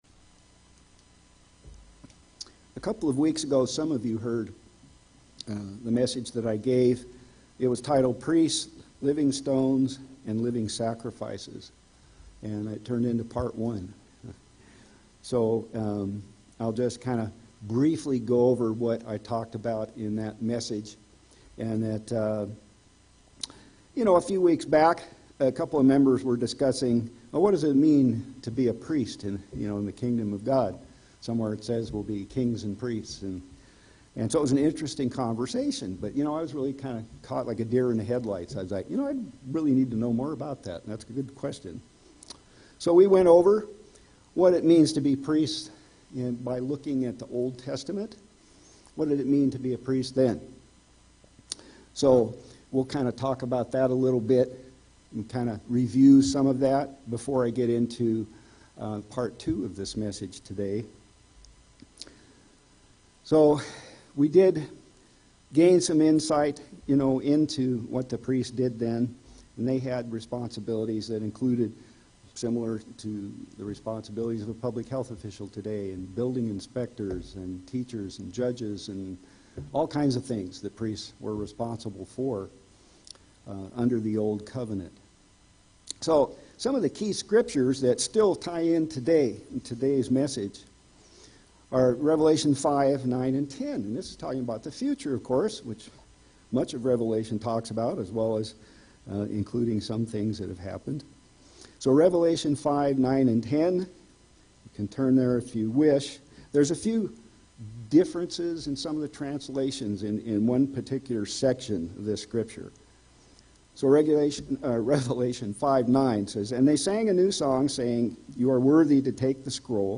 Sermons
Given in Central Oregon